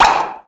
BP_14_SFX_Rope_Detach.ogg